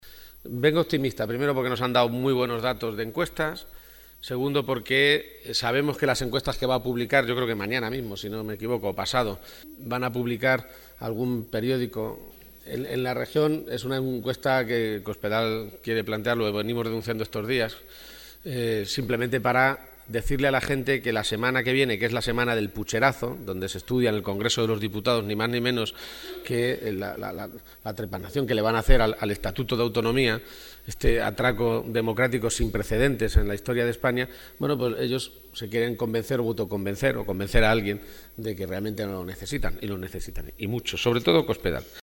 Momento de la intervención de García-Page